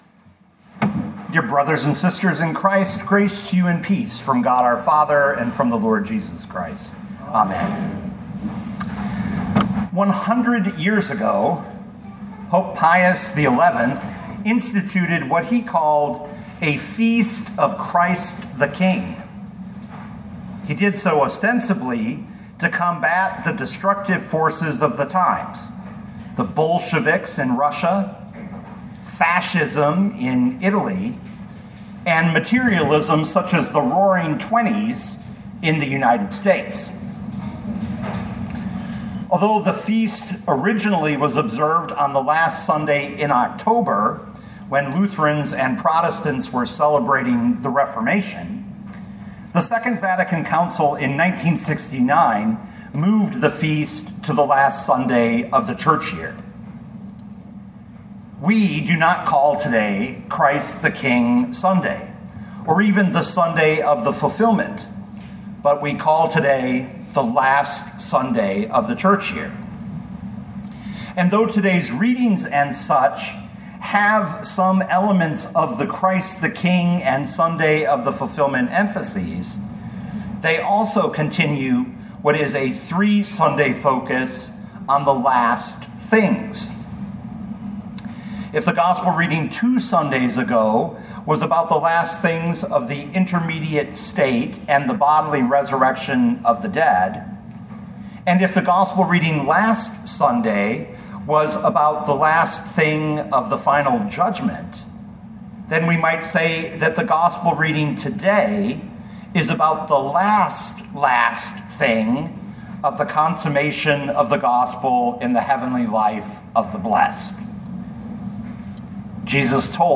2025 Luke 23:27-43 Listen to the sermon with the player below